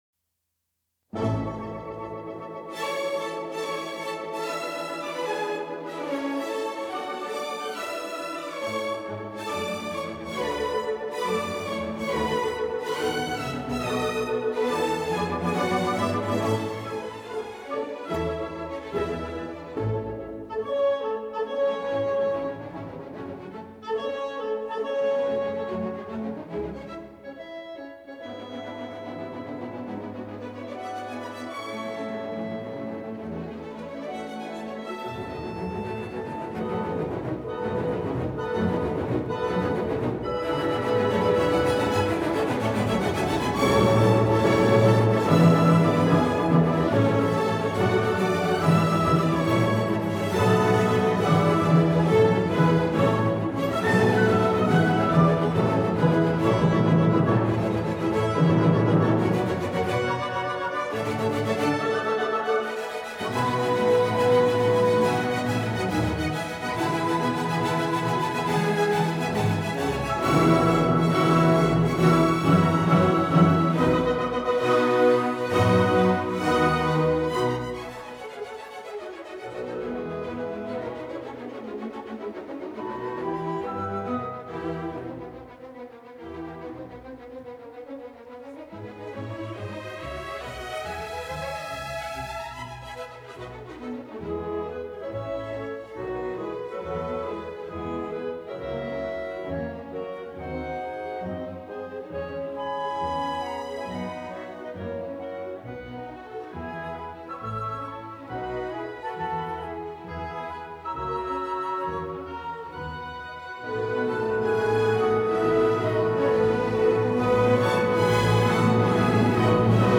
from tape